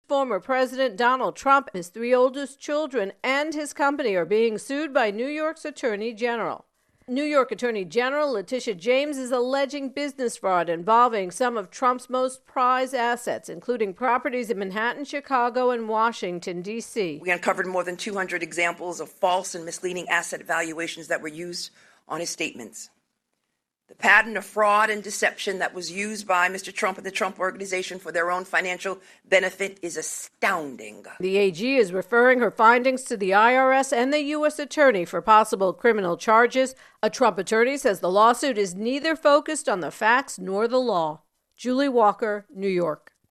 reports on Trump Legal Troubles.